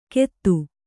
♪ kettu